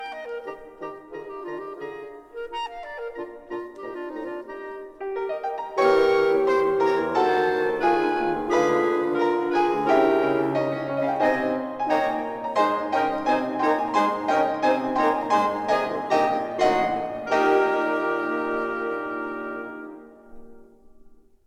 This is a stereo recording